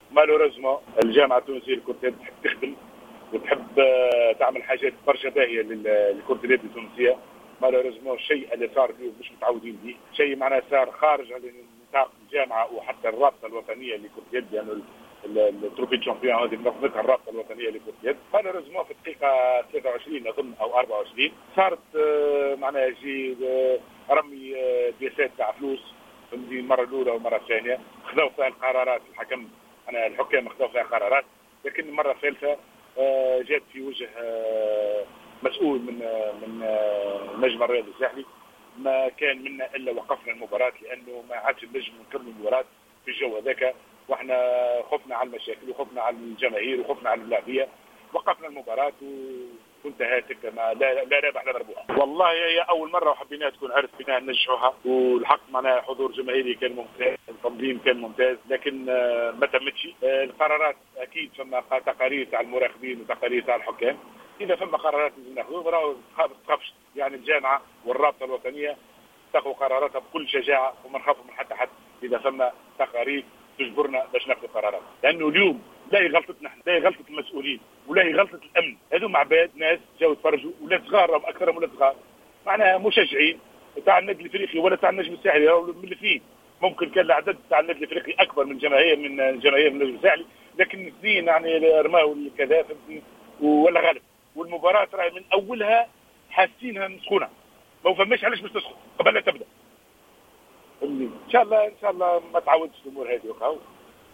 تصريح لجوهرة أف أم